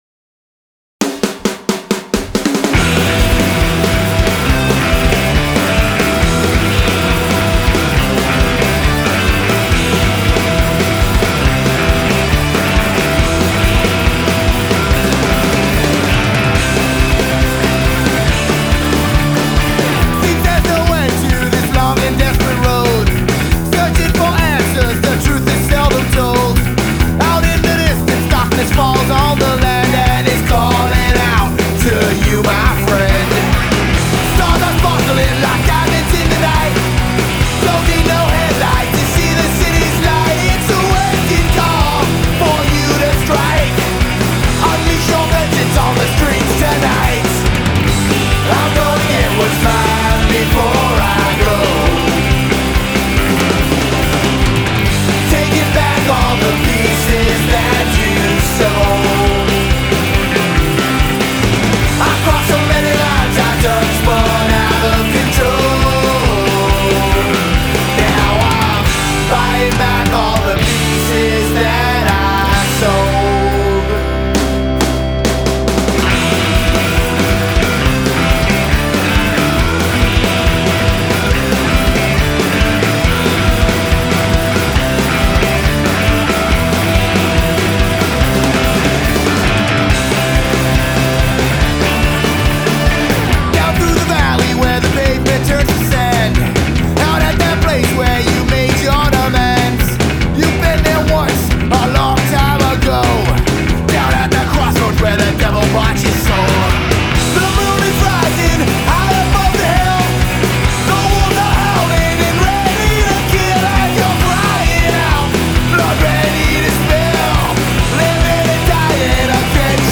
10 tracks of even faster paced Country